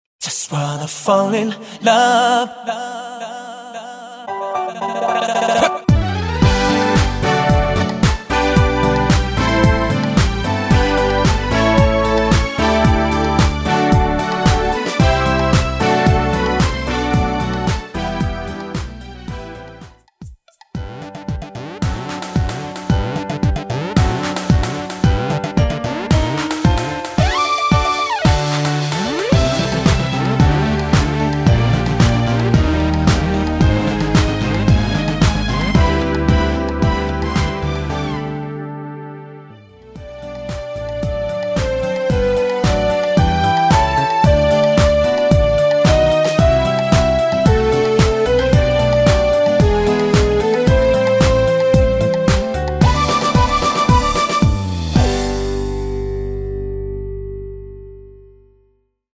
엔딩이 길어서 라이브 하시기좋도록 짧게편곡 하였습니다.(미리듣기 참조)
앞부분30초, 뒷부분30초씩 편집해서 올려 드리고 있습니다.
중간에 음이 끈어지고 다시 나오는 이유는